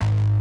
Synth Bass (Power).wav